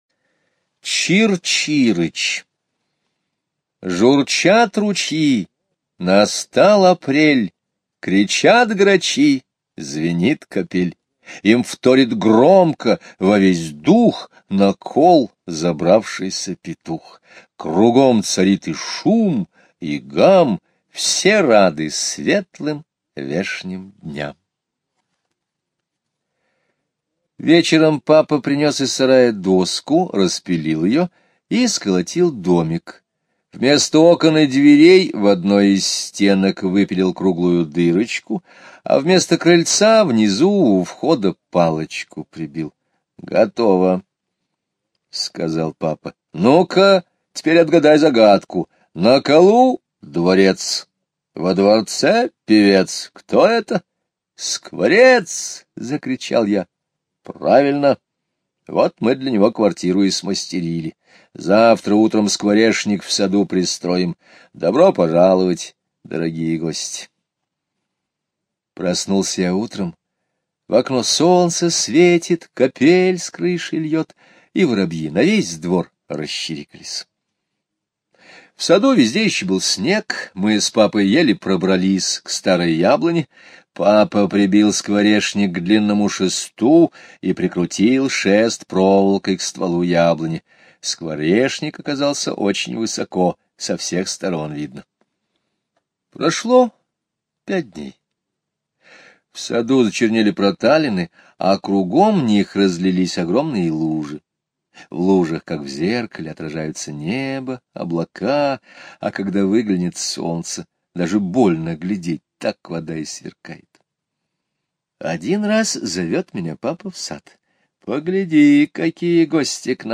Слушайте Чир-Чирыч - аудио рассказ Скребицкого Г. Рассказ про семью скворцов, которая поселилась в скворечнике в саду и стала заводить потомство.